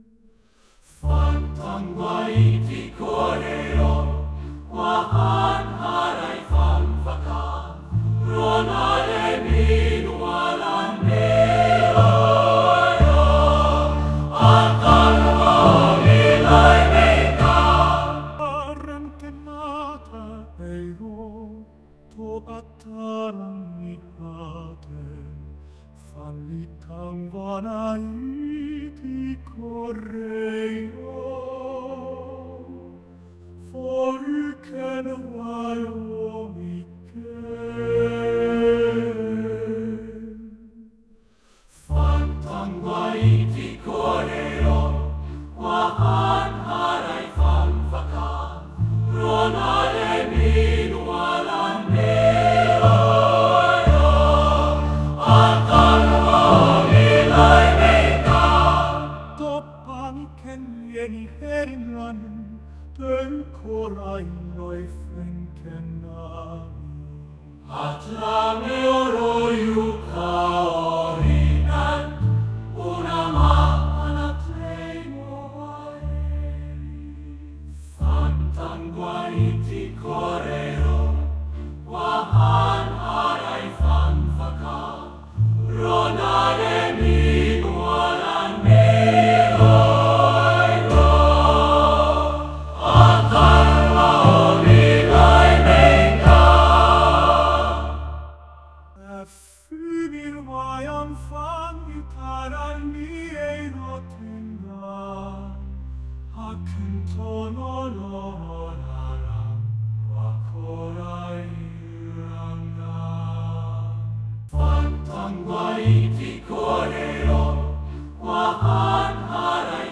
Nagranie hymnu Świętej Republiki Tangijskiej